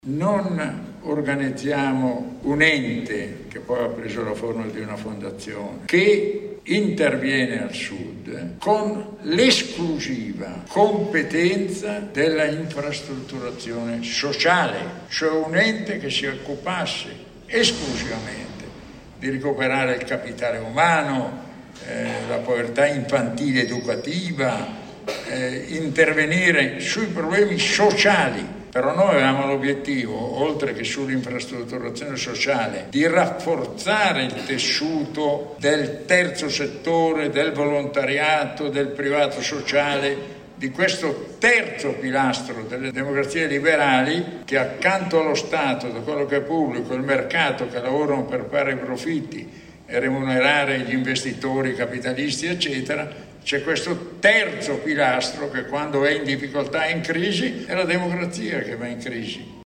Partendo da questa premessa, Fondazione con il Sud ha organizzato al Rione Parco Verde di Caivano, a Napoli, l’incontro “Un futuro già visto”.